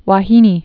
(wä-hēnē, -nā) also va·hi·ne (vä-)